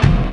0201 DR.LOOP.wav